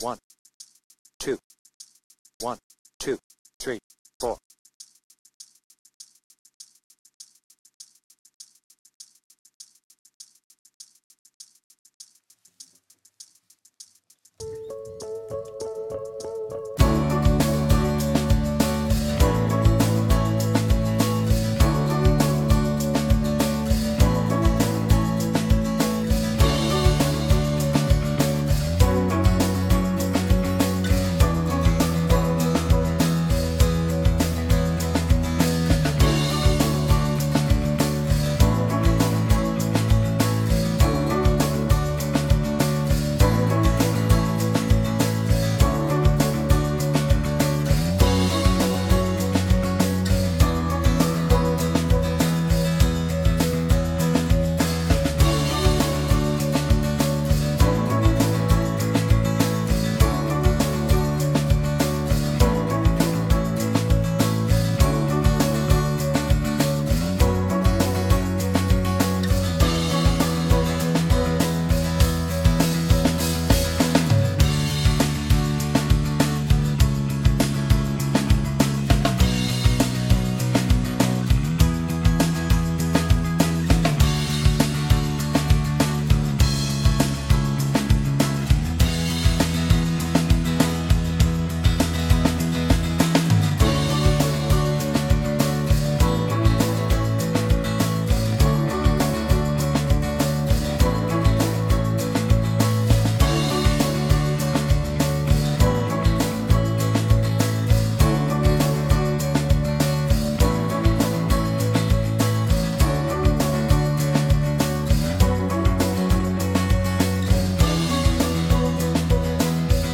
Tuning : E
Without vocals